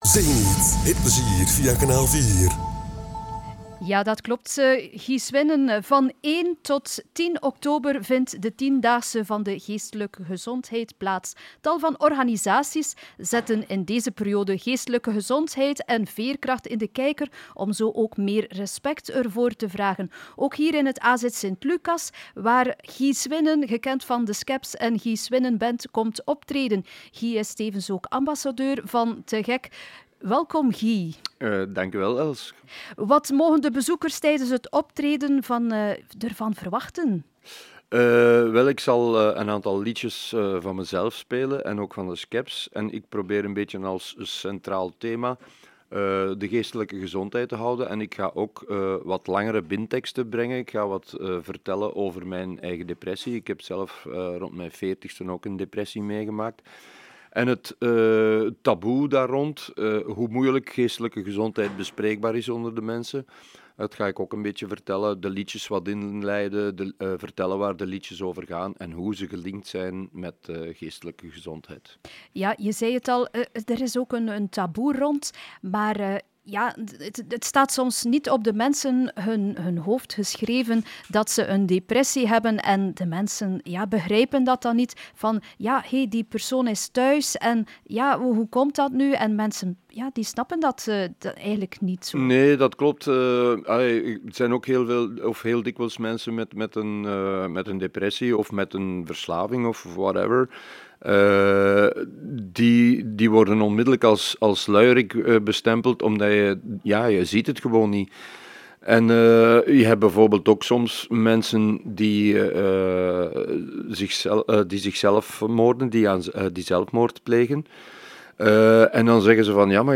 Interviews
Geestelijke gezondheid - Interview